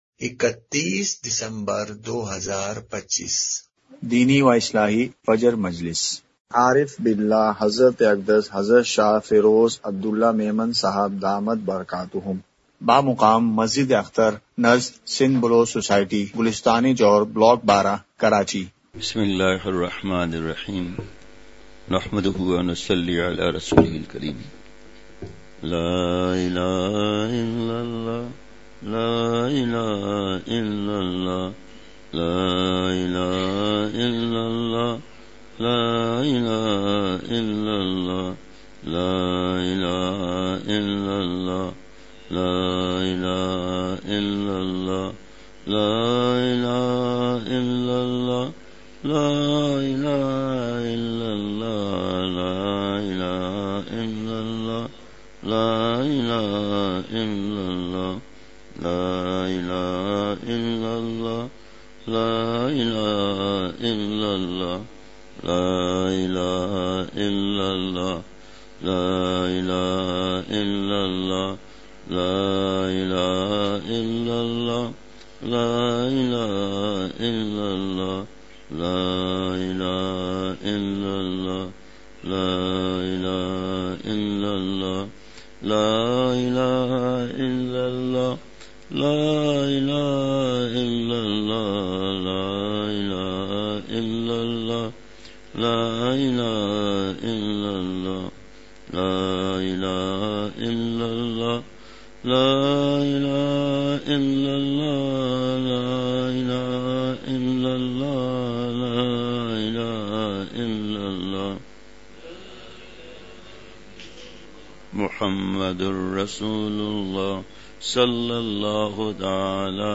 Please download the file: audio/mpeg مجلس محفوظ کیجئے اصلاحی مجلس کی جھلکیاں عنوان :ایمان کی حلاوت اور عرش کا سایہ پانےوالے خوش نصیب لوگ۔ مقام:مسجد اختر نزد سندھ بلوچ سوسائٹی گلستانِ جوہر کراچی